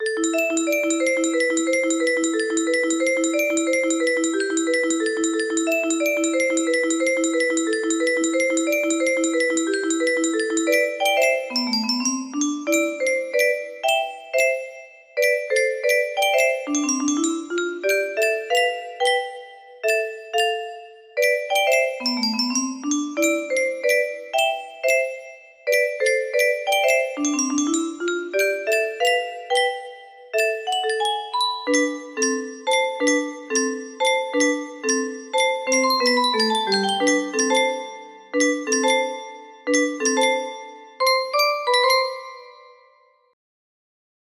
One loop version 90bpm